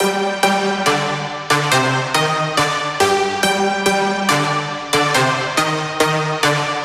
Index of /99Sounds Music Loops/Instrument Loops/Brasses